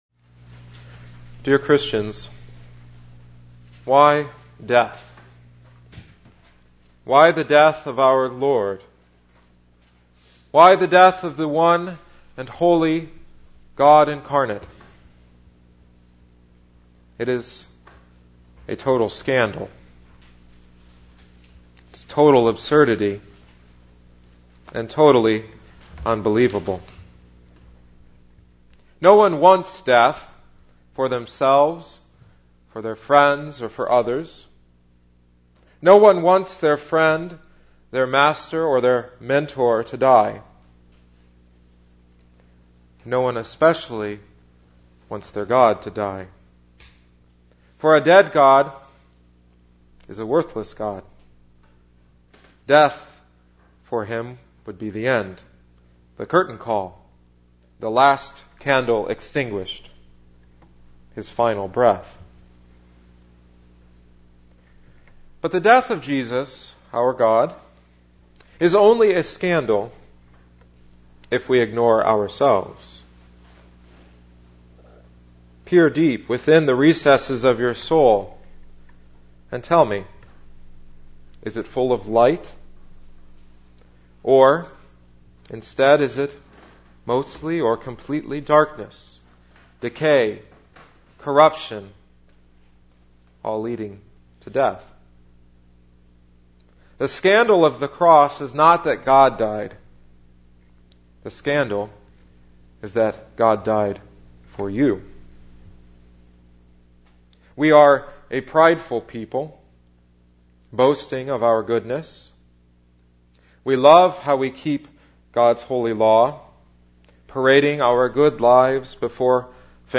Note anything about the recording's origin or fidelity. Good Friday Tenebrae